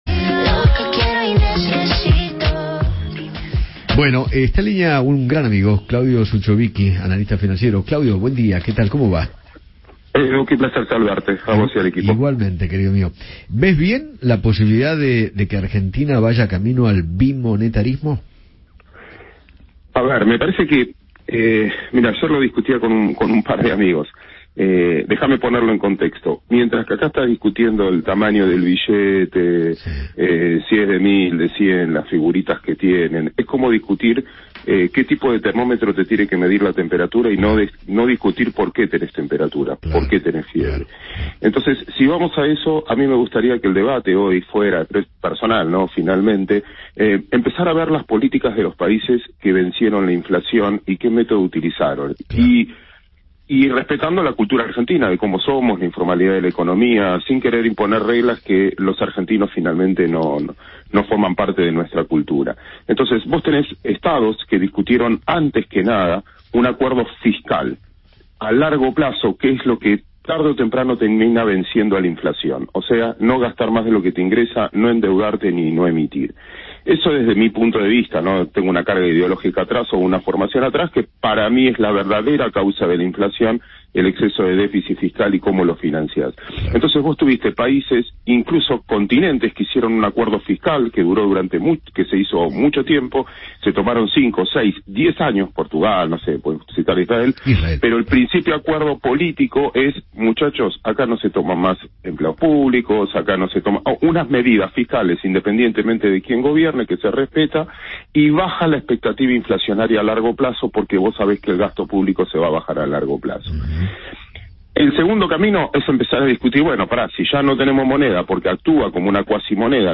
El analista financiero, Claudio Zuchovicki, dialogó con Eduardo Feinmann sobre la posibilidad de que Argentina se dirija al bimonetarismo.